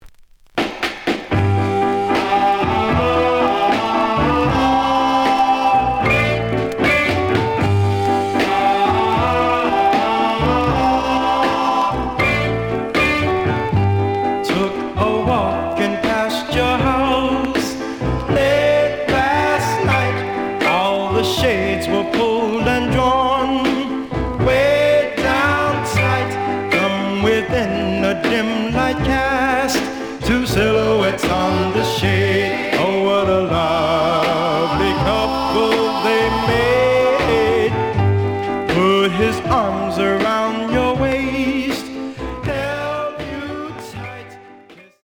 The audio sample is recorded from the actual item.
●Genre: Rhythm And Blues / Rock 'n' Roll
Some click noise on both sides due to scratches.)